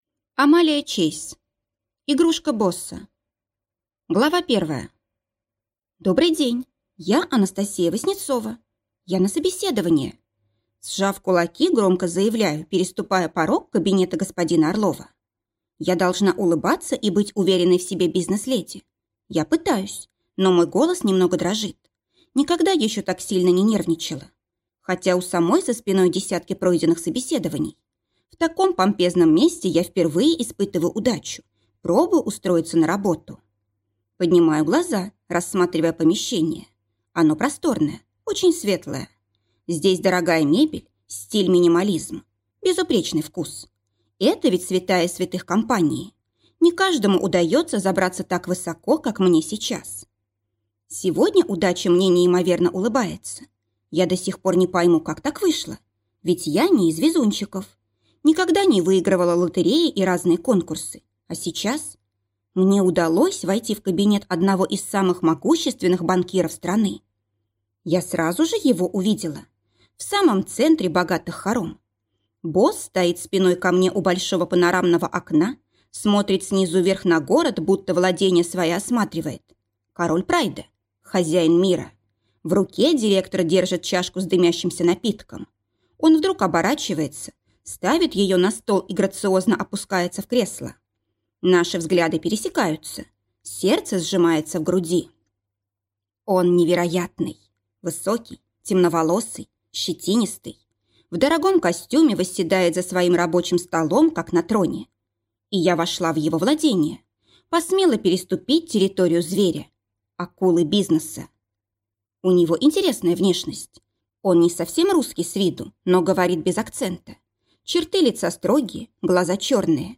Аудиокнига Игрушка босса | Библиотека аудиокниг